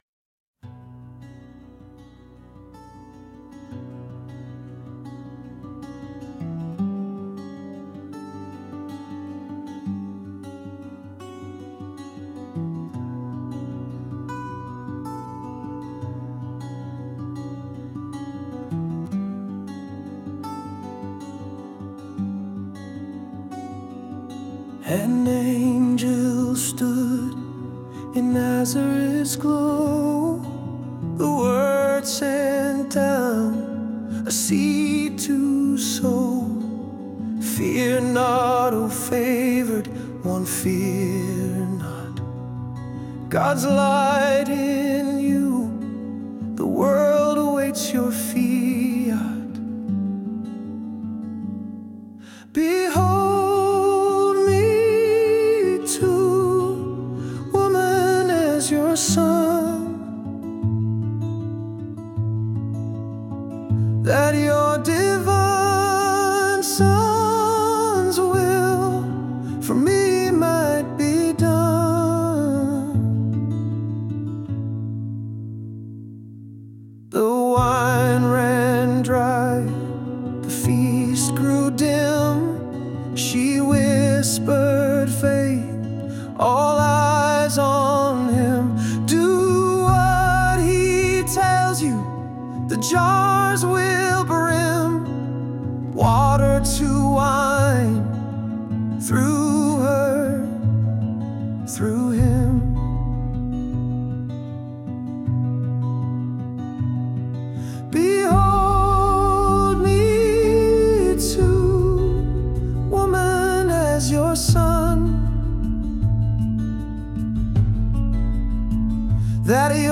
Rock ballad about Mary as our Mother